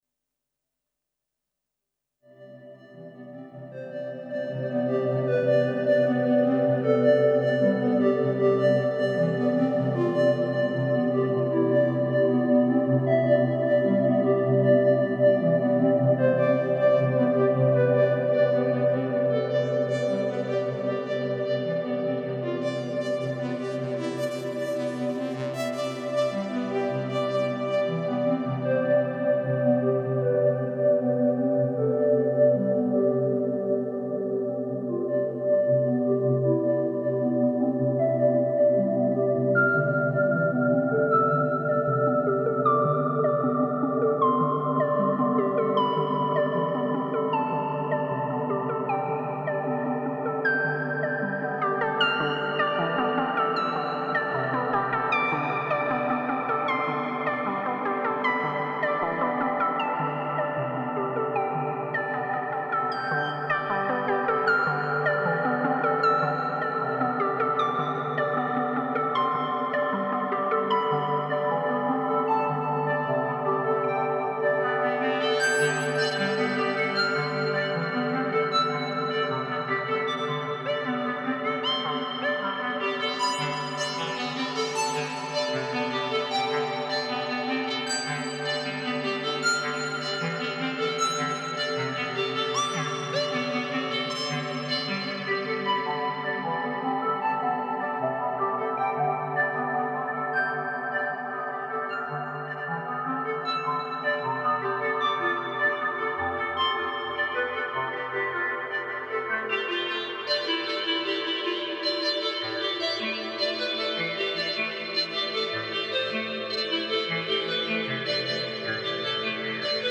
I had to test it live right away and produced a chilled sound and recorded it directly to the Zoom recorder.
Murmux Adept sequenced with my favorite reverb, the Voskhot-2M.